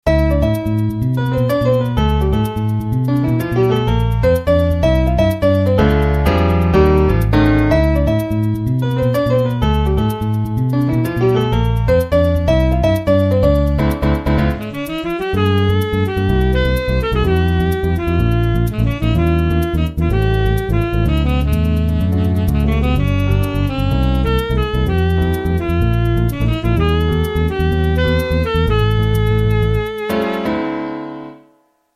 ナオコでいろんなMIDIを鳴らしてみた。
一部の楽器はすごくいい音が鳴るが、
それ以外は妙にチープだったり、そもそも鳴らなかったりする。